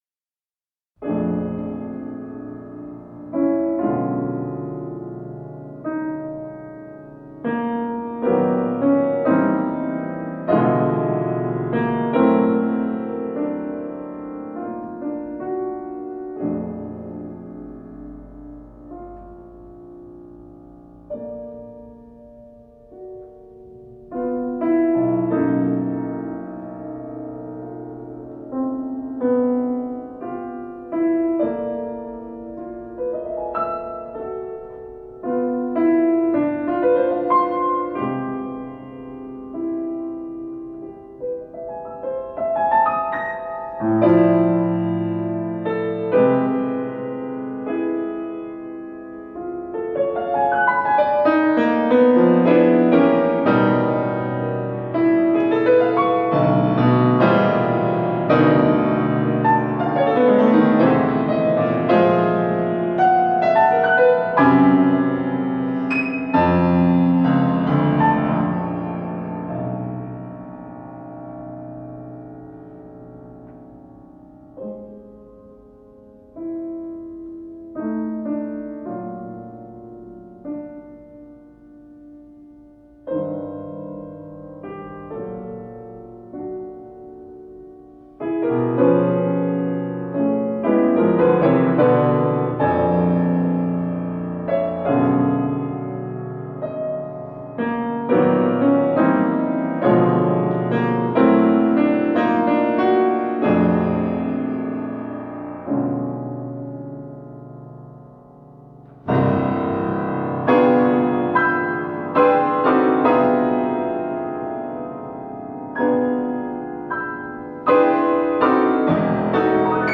Sonata Fantasía para piano